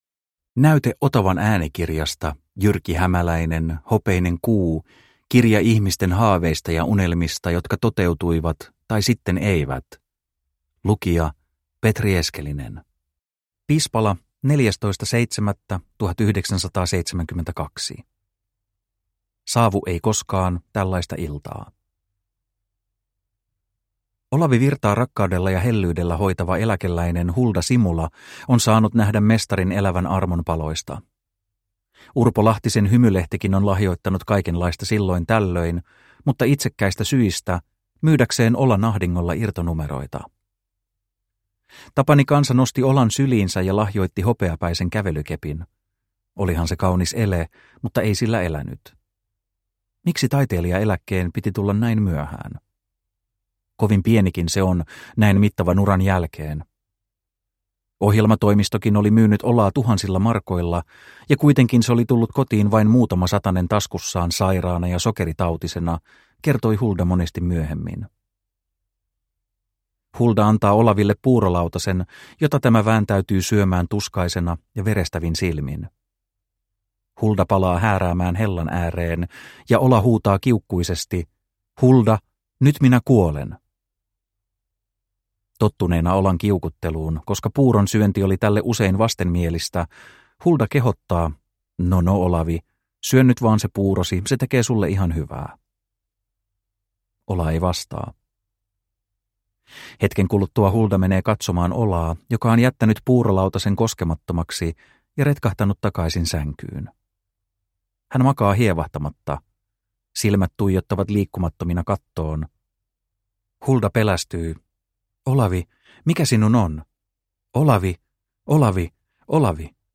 Hopeinen kuu – Ljudbok – Laddas ner